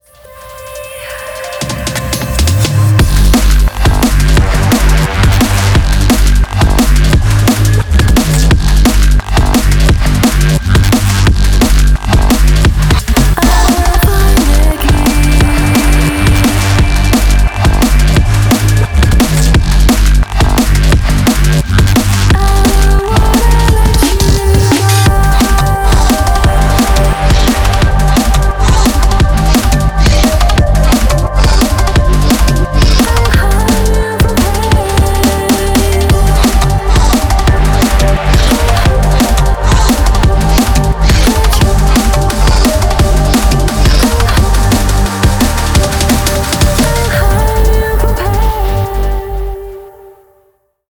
• Качество: 320, Stereo
громкие
мощные
красивый женский вокал
drum n bass
DnB